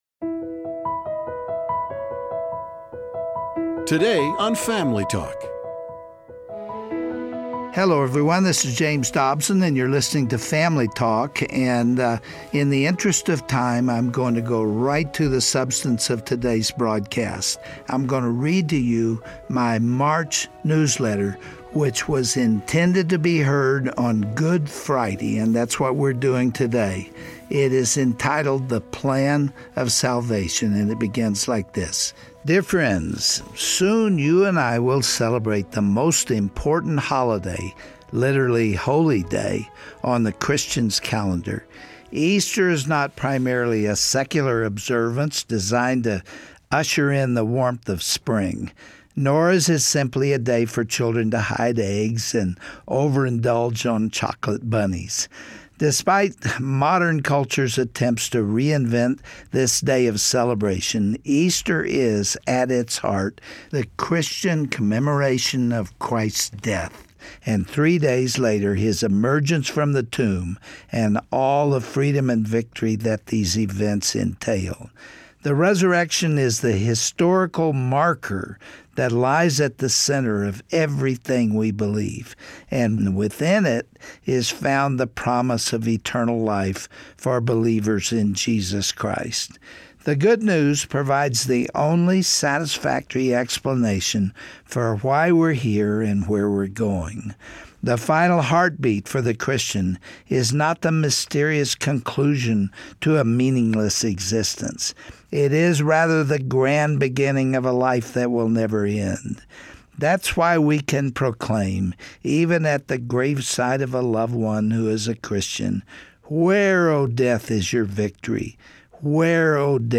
On this Good Friday edition of Family Talk, Dr. Dobson will read his March 2018 newsletter appropriately titled, The Plan of Salvation. He will share his views on why Jesus came to Earth to die for us and the redeeming power we can access through His resurrection.